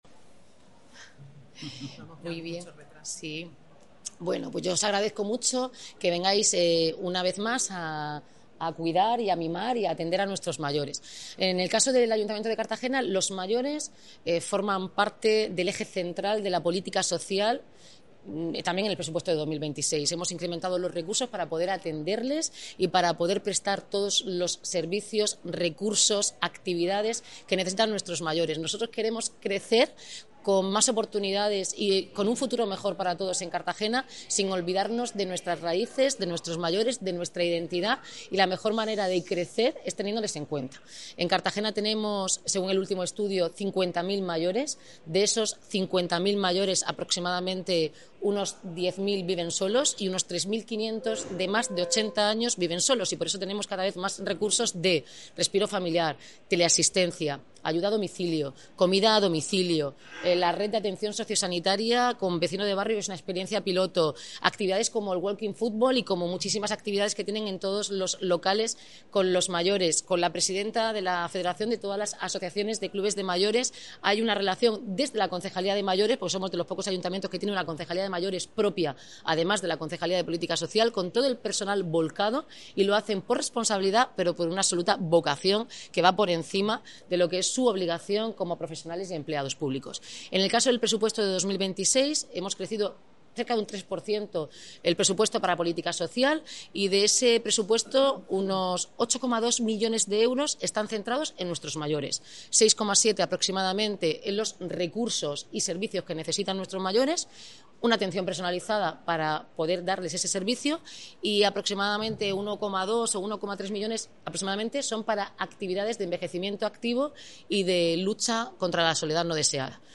El Salón de Actos ‘Isaac Peral’ del CIM acogió esta mañana la presentación de la nueva Guía de Recursos y Servicios para Personas Mayores del Ayuntamiento de Cartagena.